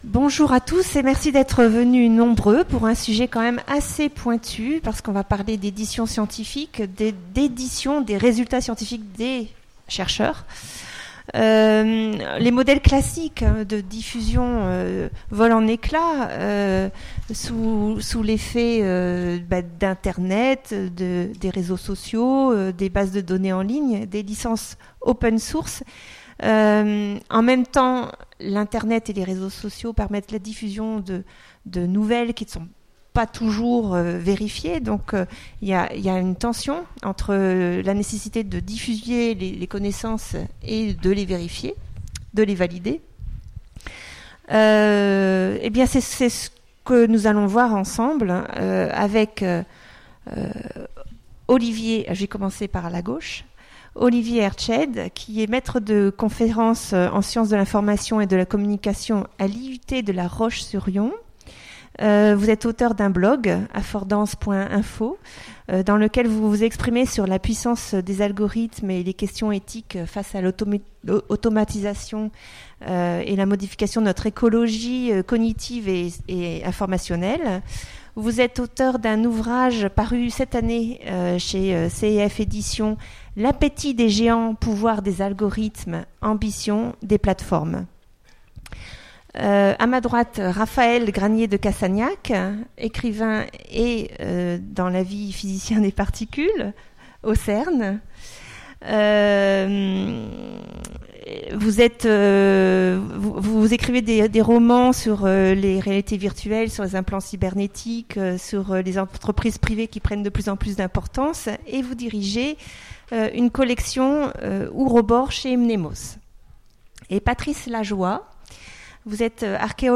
Utopiales 2017 : Conférence L’avenir de l’édition scientifique